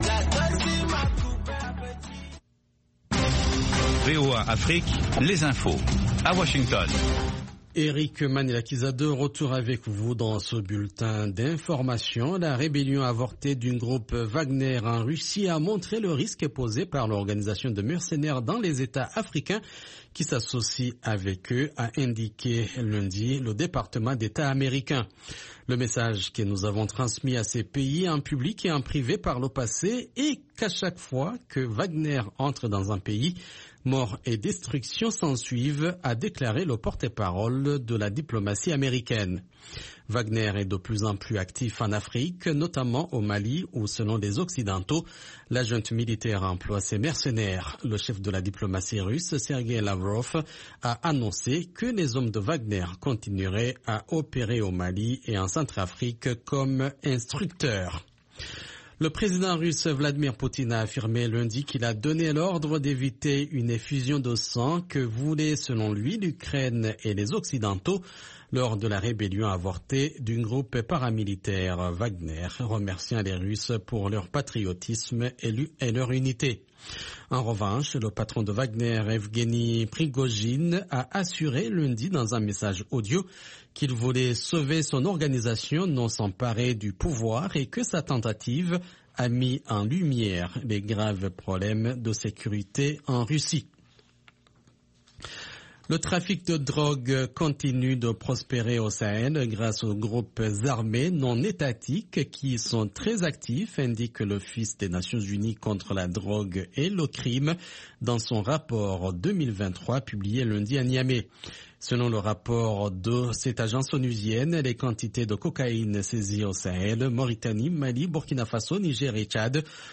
Bulletin d’information de 13 heures